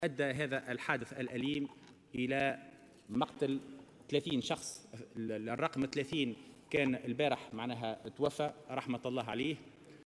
Lors de la plénière, qui se tient actuellement au Bardo, le ministre de l'Intérieur, Hichem Fourati a confirmé que le bilan des victimes du tragique accident de Amdoun, est passé à 30 morts.
Ministre de l'Intérieur t√©l√©charger partager sur